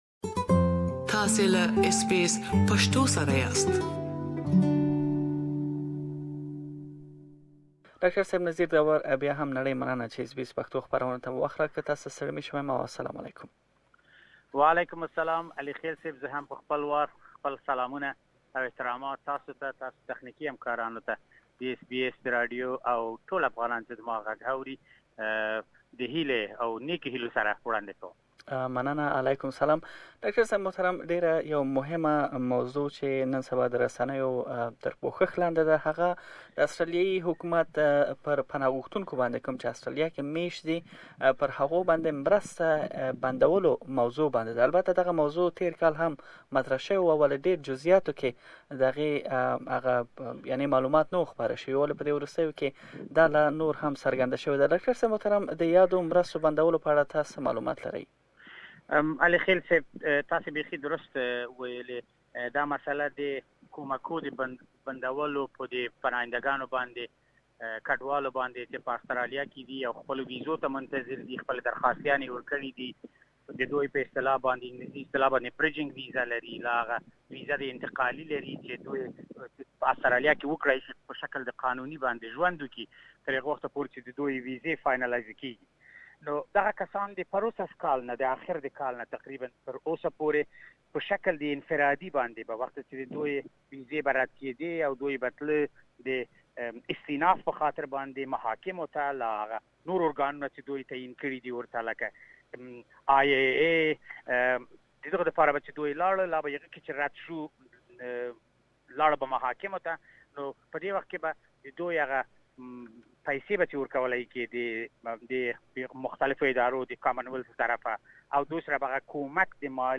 مرکه کړې چې تاسې دا مرکه دلته اوريدلی شئ.